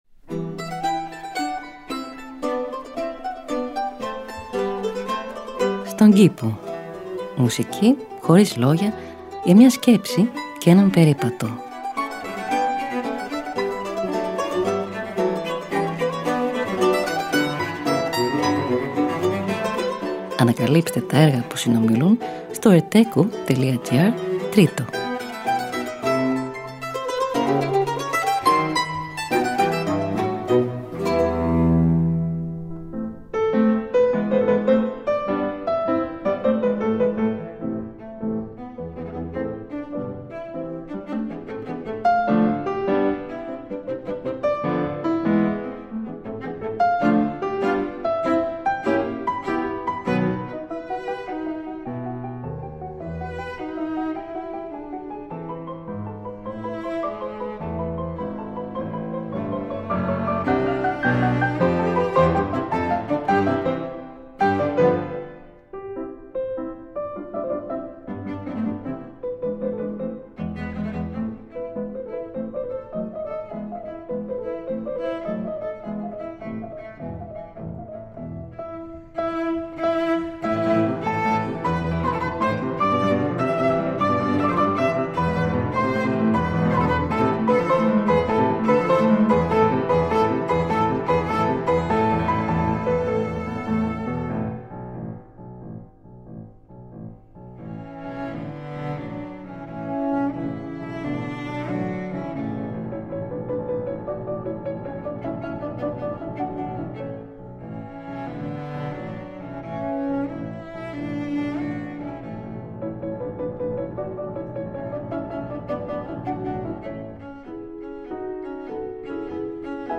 Μουσική Χωρίς Λόγια για μια Σκέψη και έναν Περίπατο.
Allegro – Arrange for mandolin and continuo: Avi Avital